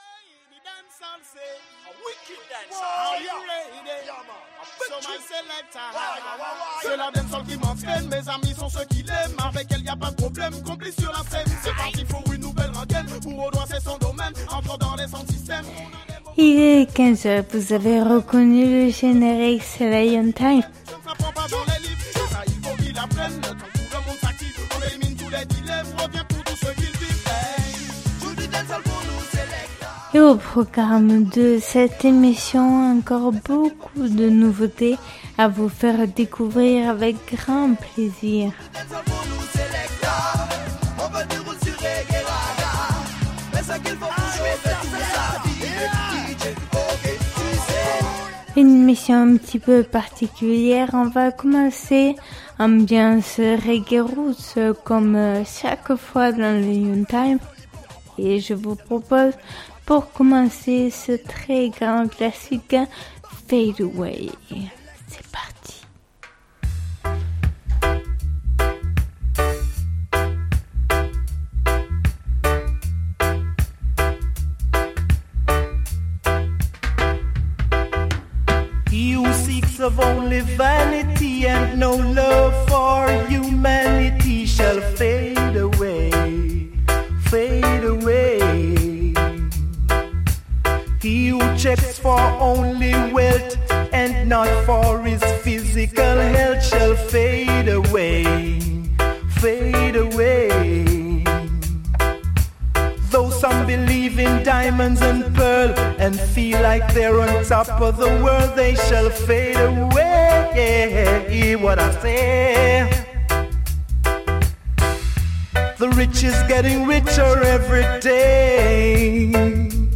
Émission qui invite au voyage et à la découverte du roots, du reggae et du dancehall.